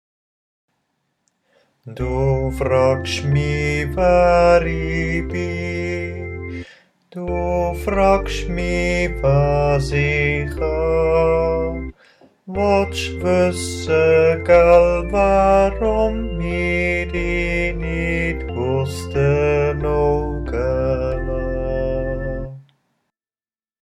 5_dufragschmi_alt.mp3